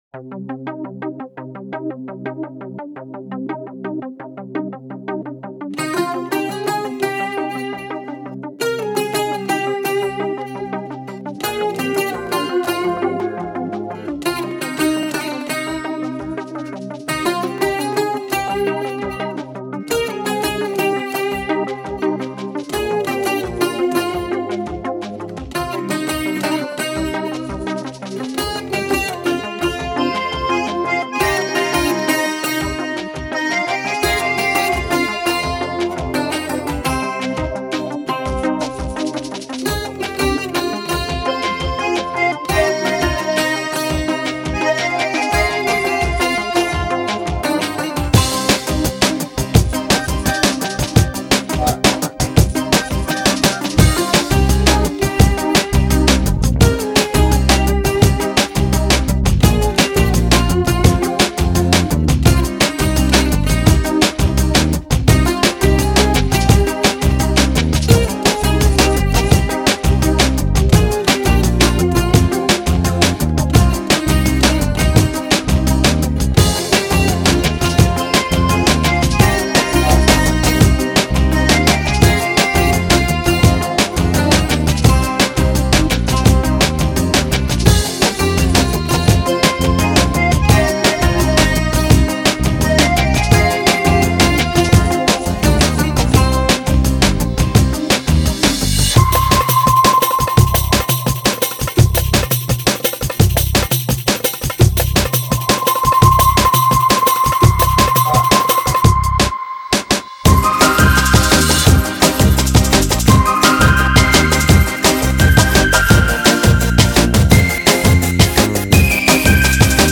Нью-эйдж